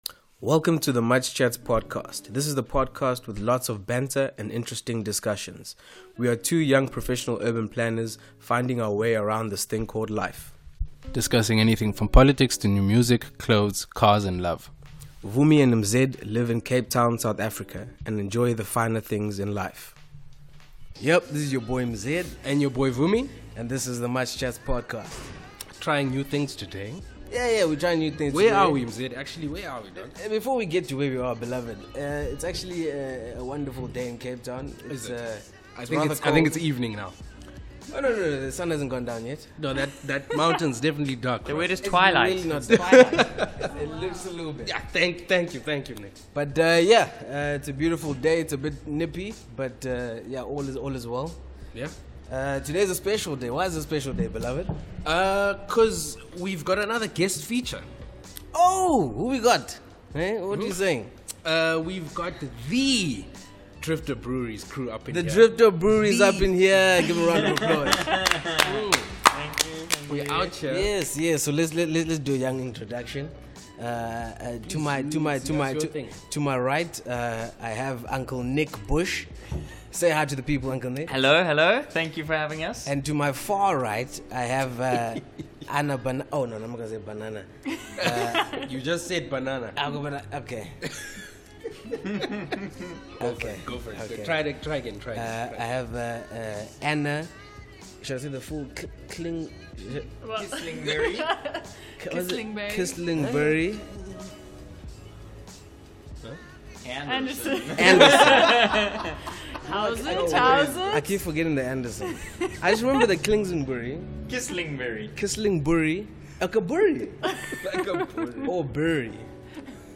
The boys even get the chance to taste some exclusive brews, with the unique flair of Drifter Breweries! They also share with us their passion for their community and events!